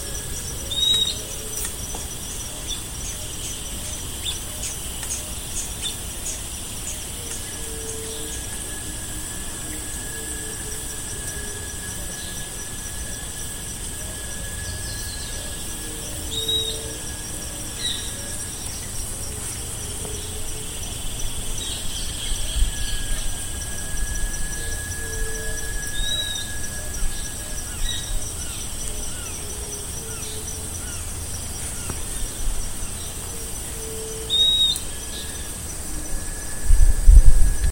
Southern Beardless Tyrannulet (Camptostoma obsoletum)
Location or protected area: Reserva Natural del Pilar
Condition: Wild
Certainty: Recorded vocal
piojito-silbon.mp3